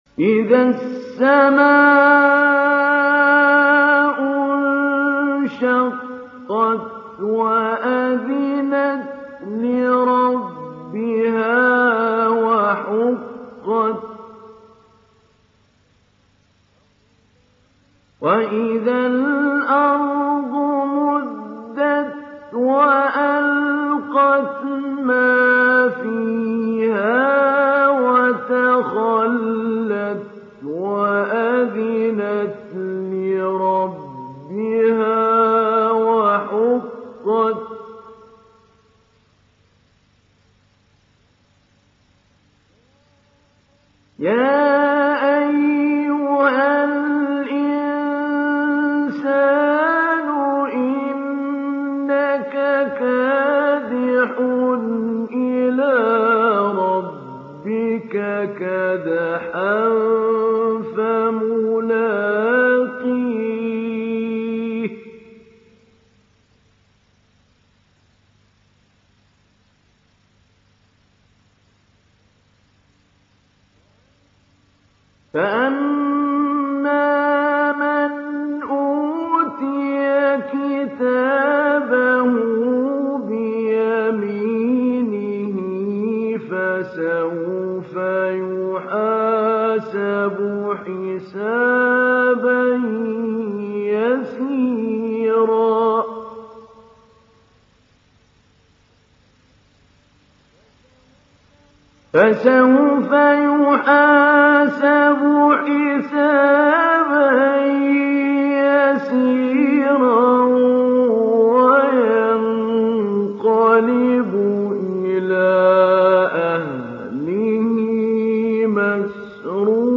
Sourate Al Inshiqaq Télécharger mp3 Mahmoud Ali Albanna Mujawwad Riwayat Hafs an Assim, Téléchargez le Coran et écoutez les liens directs complets mp3
Télécharger Sourate Al Inshiqaq Mahmoud Ali Albanna Mujawwad